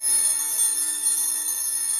CHAIN CYM -S.WAV